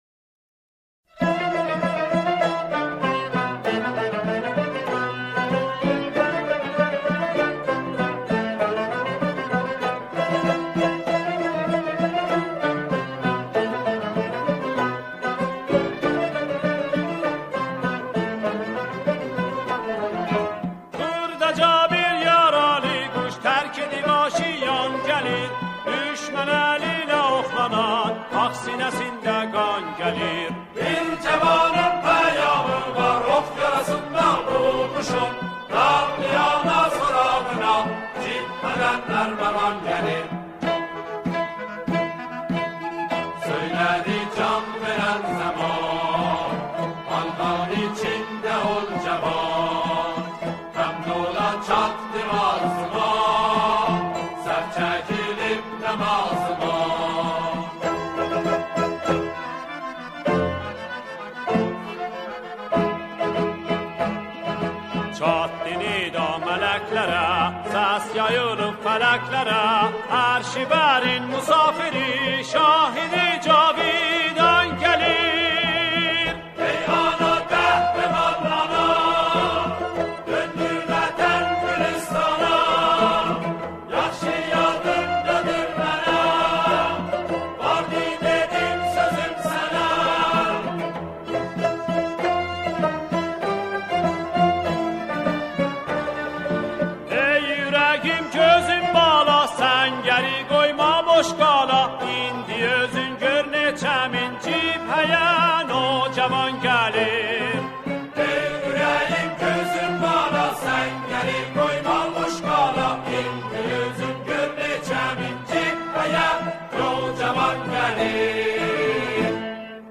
همخوانی شعری قدیمی